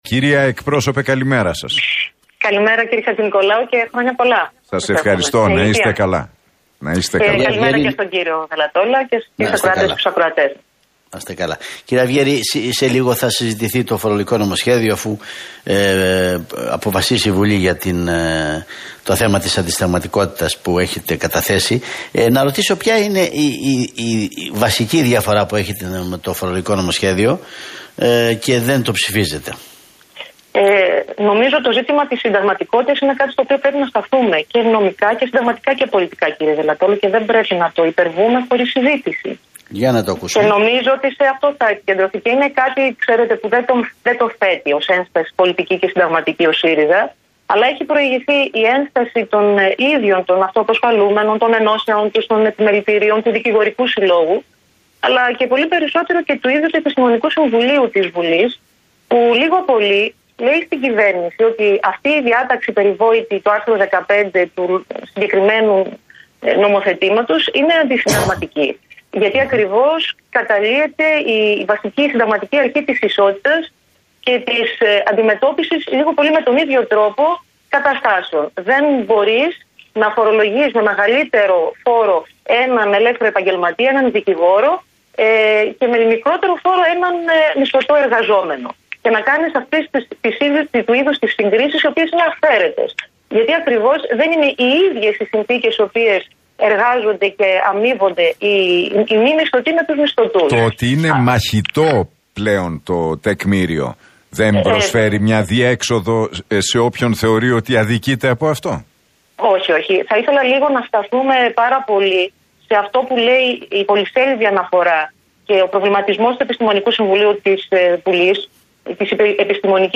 Αυγέρη στον Realfm 97,8: Με την επιβολή οριζόντιου χαρατσιού, δεν καταπολεμείται η φοροδιαφυγή